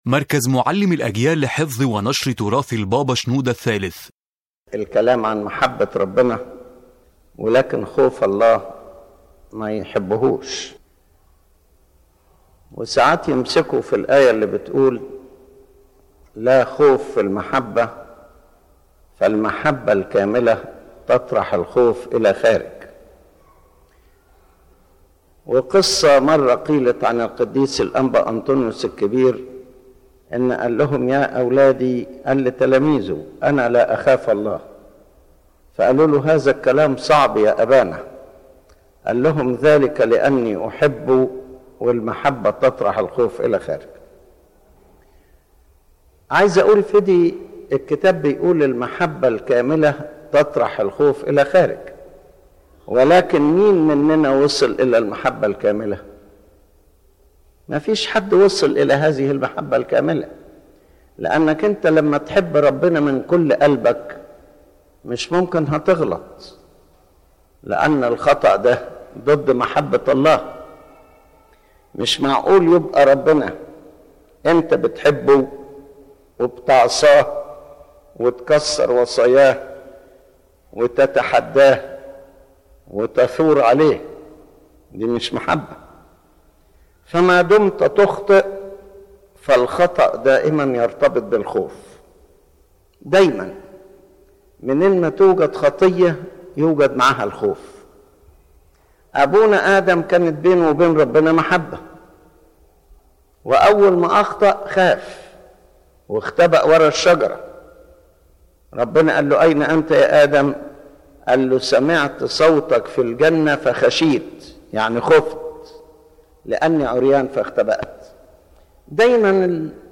The lecture explains the deep relationship between the love of God and the fear of God, emphasizing that perfect love removes fear, but this level of love is rarely reached by a person.